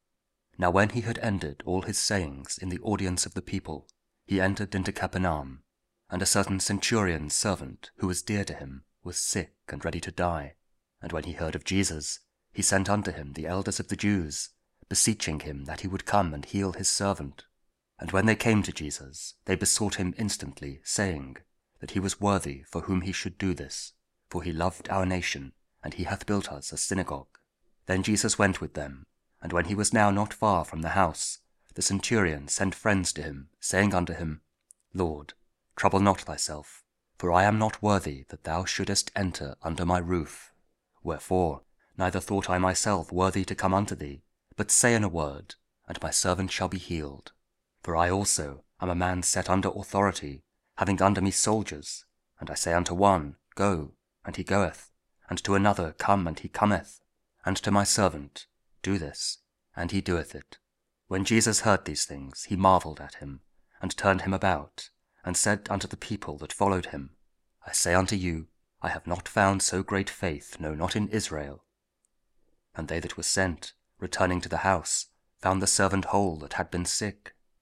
Luke 7: 1-10 – Week 24 Ordinary Time, Monday (King James Audio Bible KJV, Spoken Word)